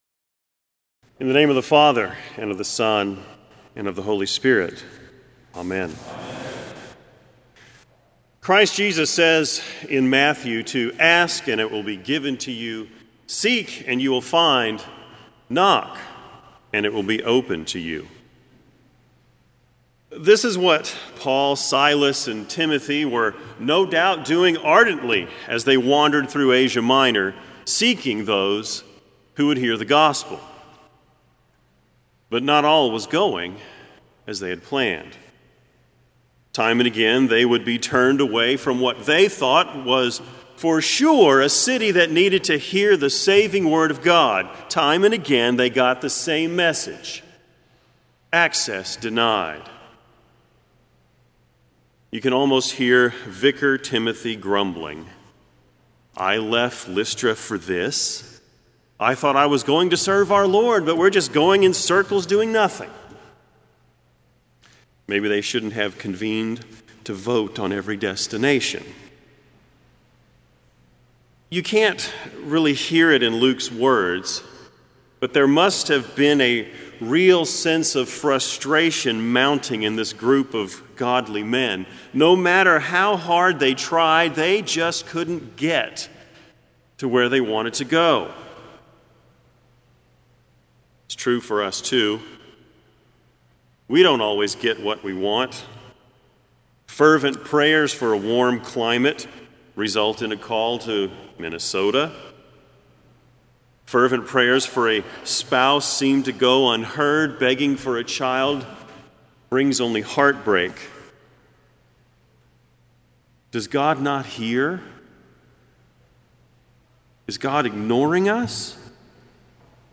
Kramer Chapel Sermon - July 20, 2007